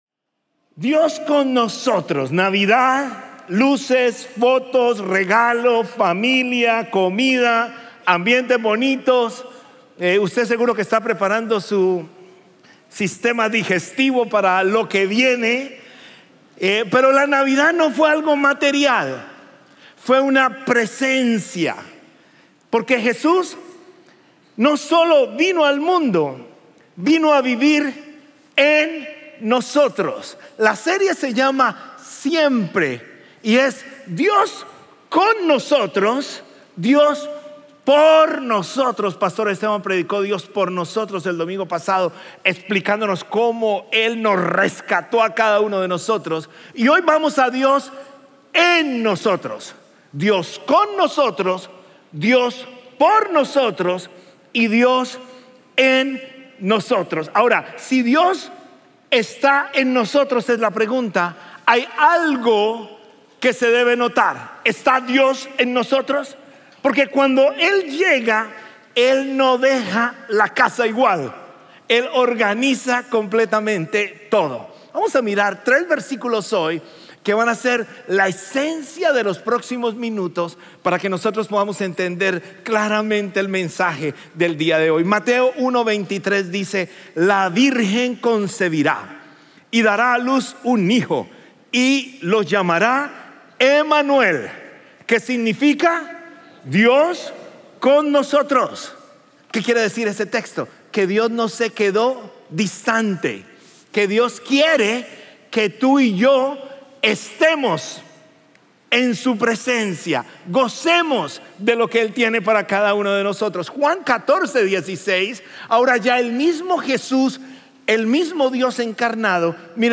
Sermones Conroe – Media Player